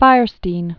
(fīrstēn), Harvey Born 1954.